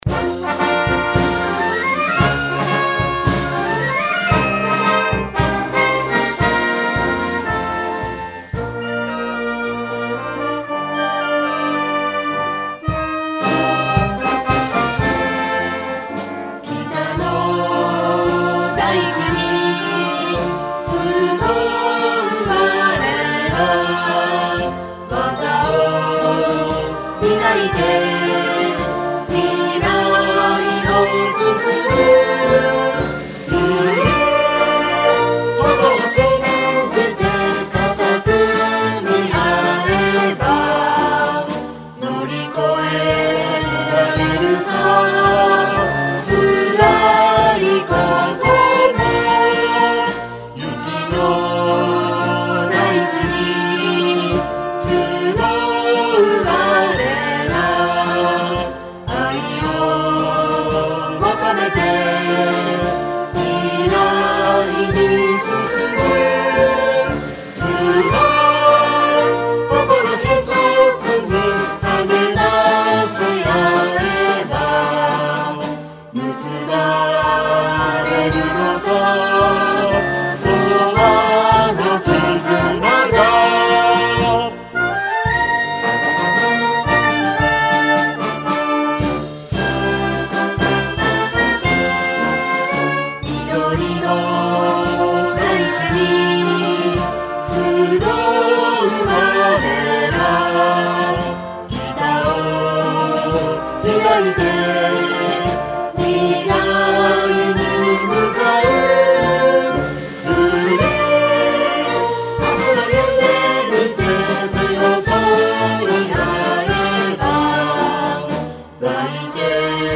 北海道名寄産業高等学校 校歌（歌有）.mp3